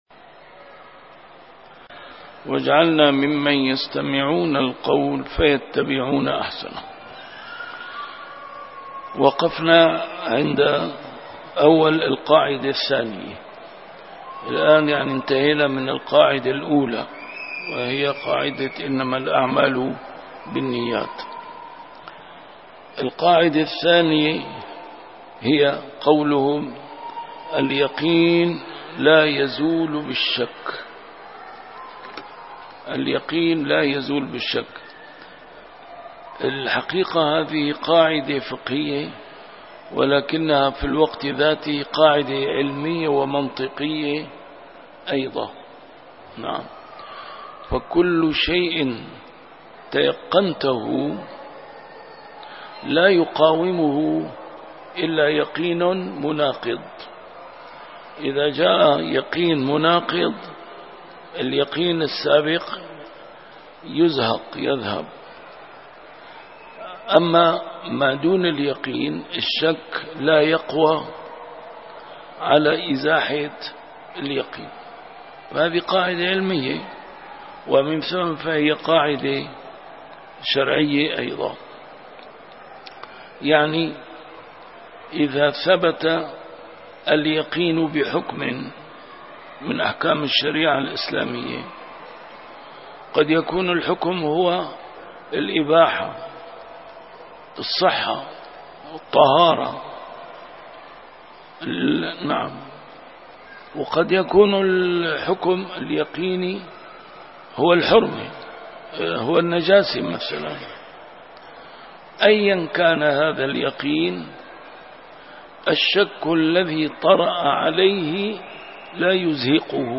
A MARTYR SCHOLAR: IMAM MUHAMMAD SAEED RAMADAN AL-BOUTI - الدروس العلمية - كتاب الأشباه والنظائر للإمام السيوطي - كتاب الأشباه والنظائر، الدرس الثامن والعشرون: ما يتأدى فيه الفرض بنية النفل.